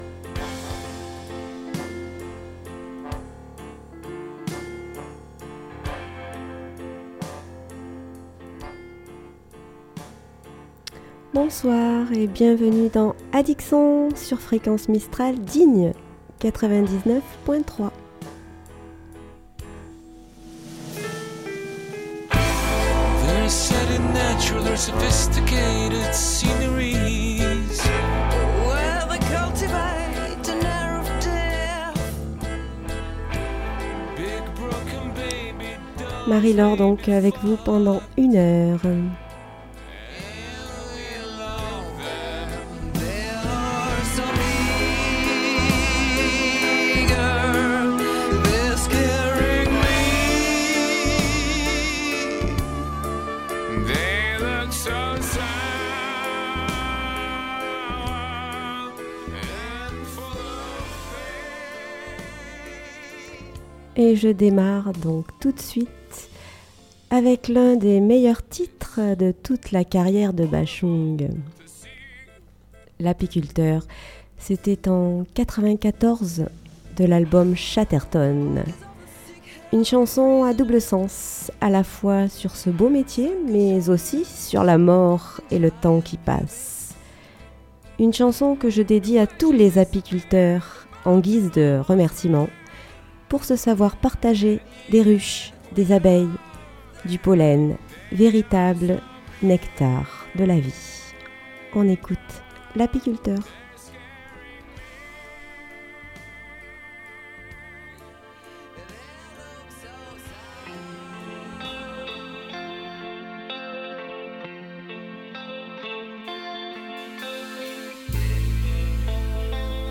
Addic Son - Emission musicale du 21 février